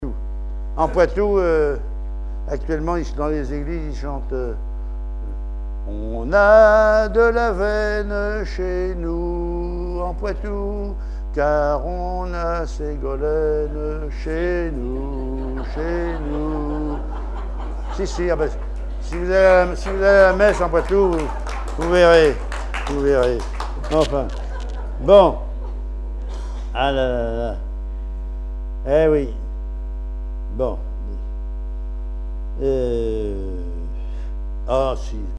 Langue Patois local
Genre sketch